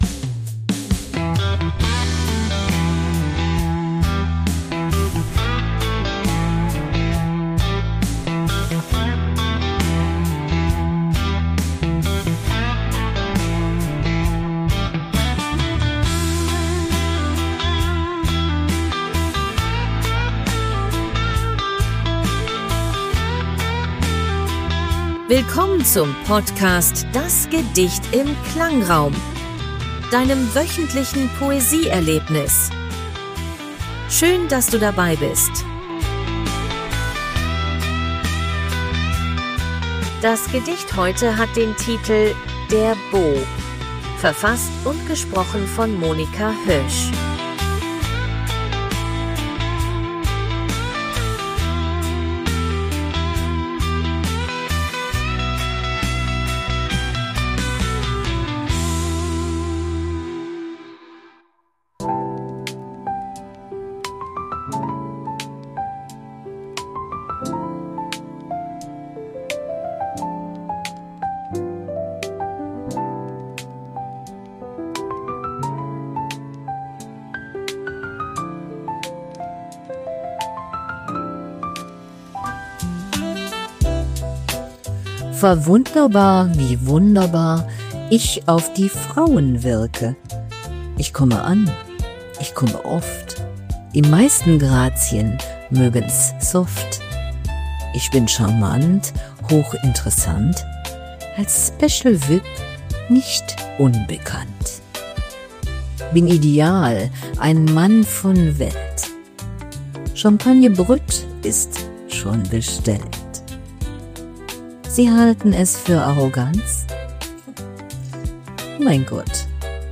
von KI-generierter Musik.